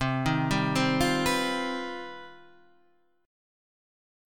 C Minor Major 11th